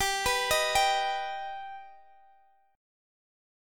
Listen to G+ strummed